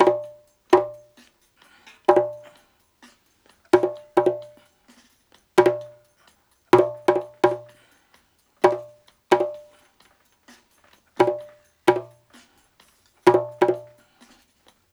129-BONGO1.wav